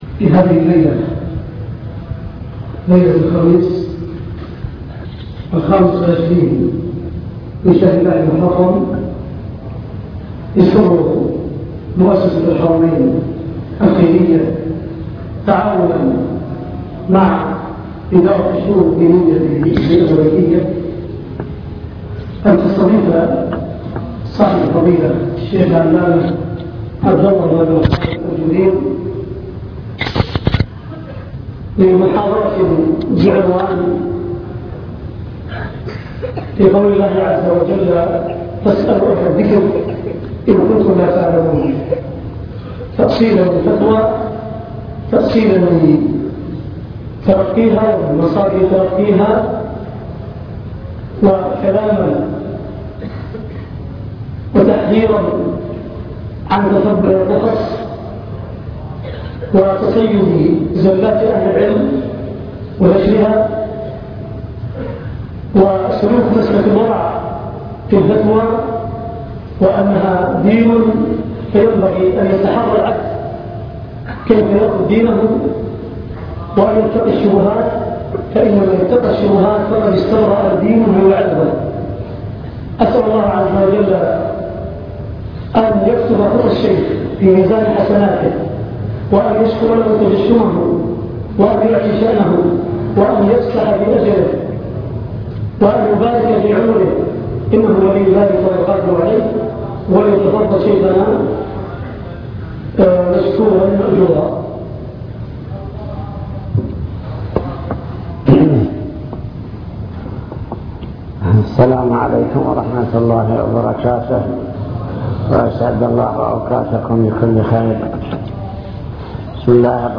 المكتبة الصوتية  تسجيلات - لقاءات  فاسألوا أهل الذكر